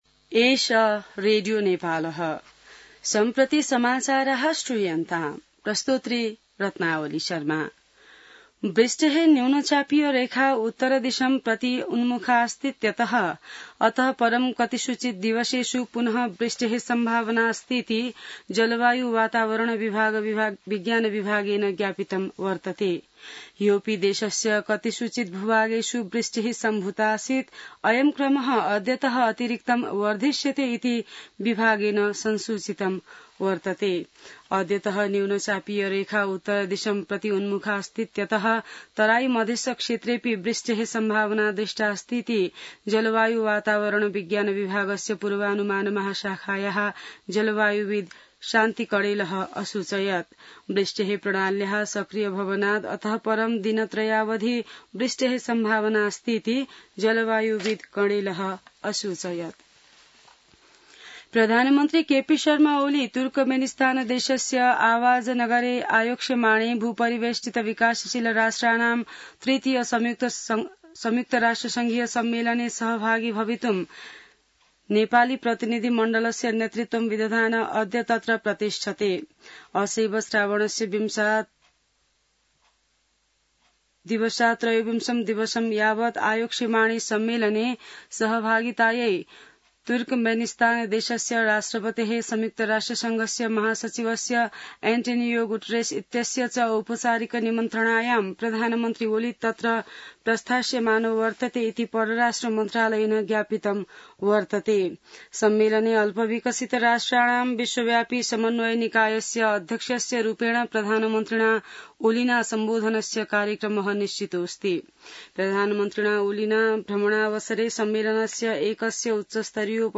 संस्कृत समाचार : १८ साउन , २०८२